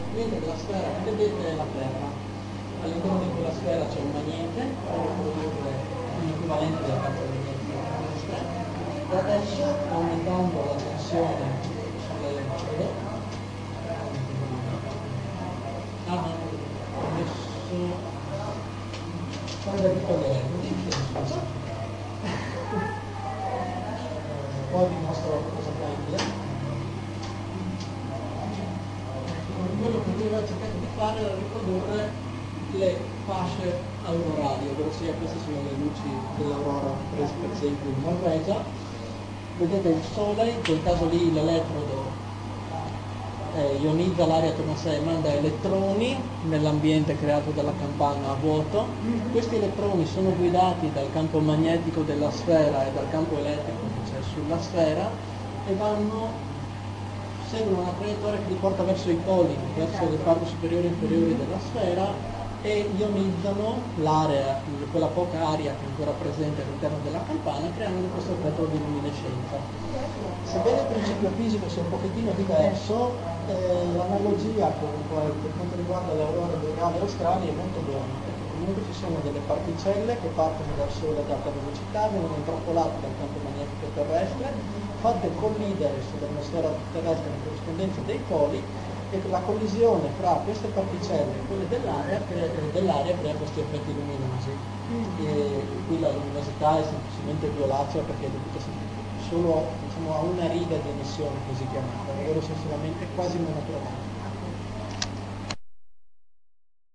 OPEN DAY